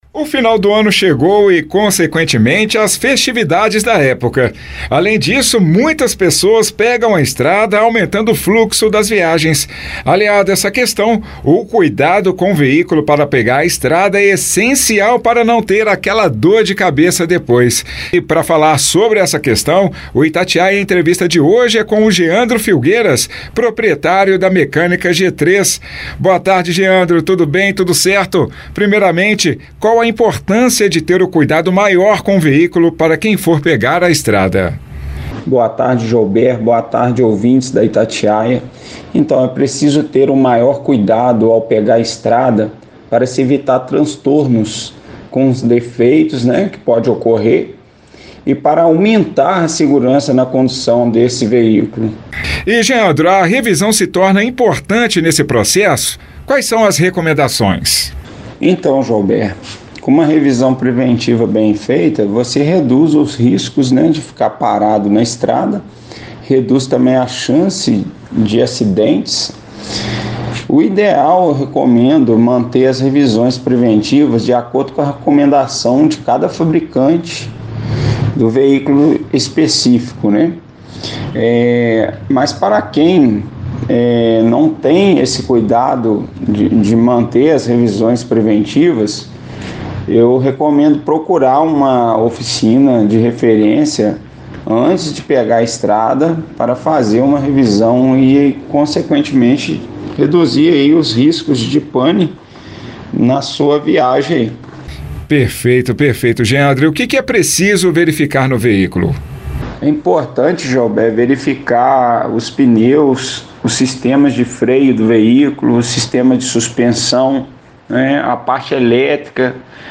19.12_Itatiaia-Entrevista-revisao-de-carro-para-viagem.mp3